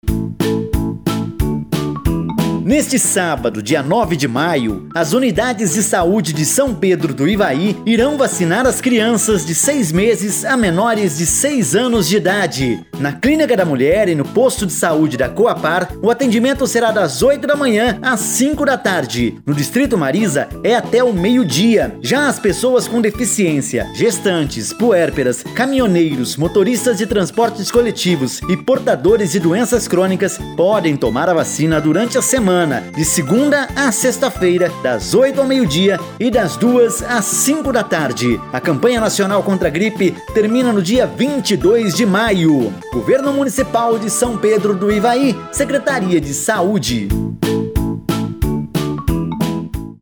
CHAMADA PARA RADIO